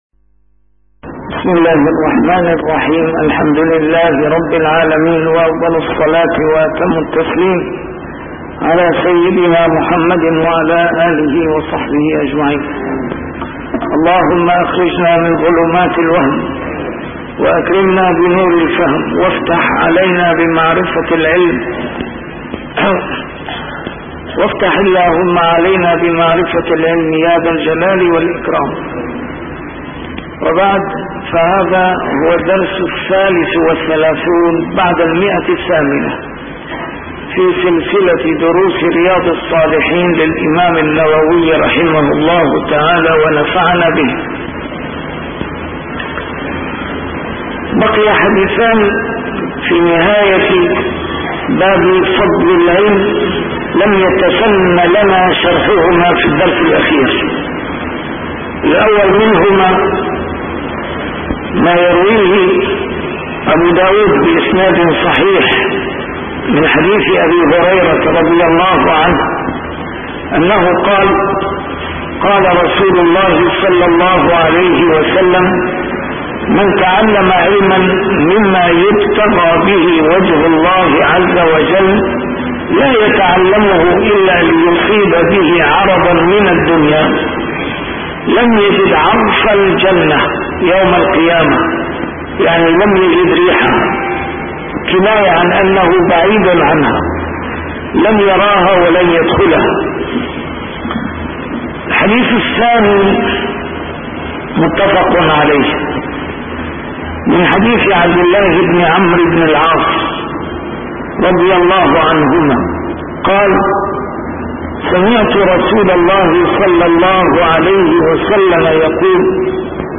A MARTYR SCHOLAR: IMAM MUHAMMAD SAEED RAMADAN AL-BOUTI - الدروس العلمية - شرح كتاب رياض الصالحين - 833- شرح رياض الصالحين: فضل العلم